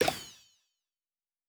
Special & Powerup (40).wav